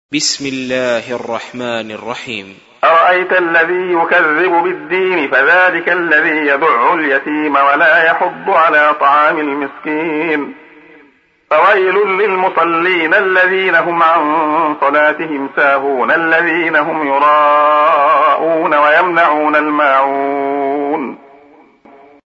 سُورَةُ المَاعُونِ بصوت الشيخ عبدالله الخياط